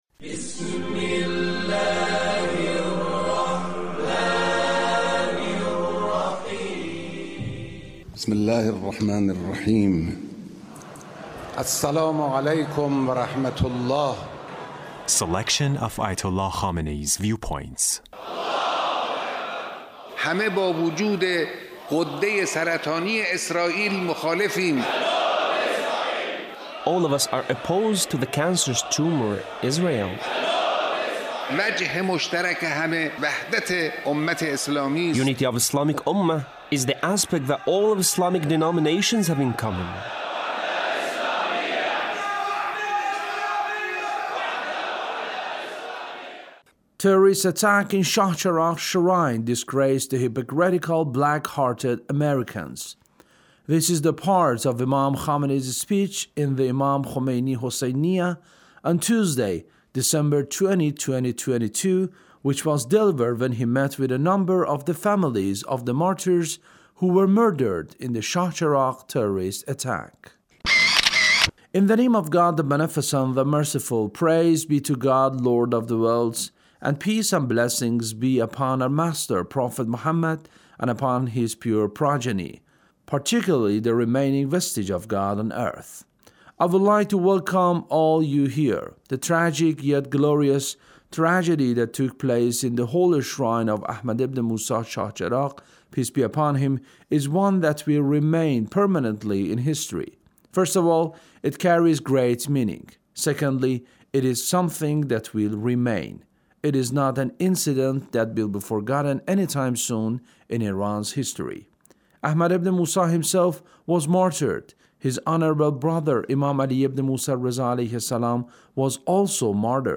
Leader's Speech (1604)
Leader's Speech about Chahcheragh Terrorist Attack